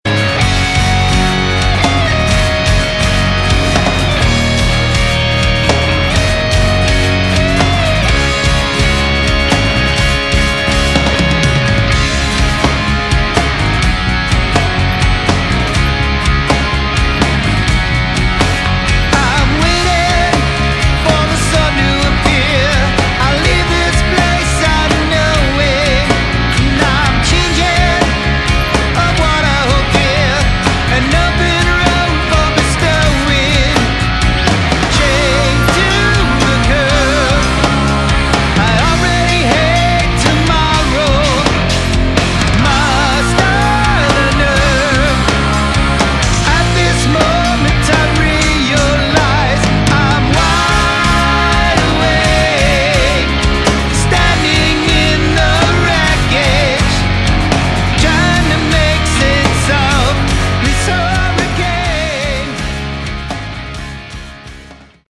Category: Prog Rock
lead vocals, acoustic guitar
guitars
drums, backing vocals
bass, keyboards, backing vocals.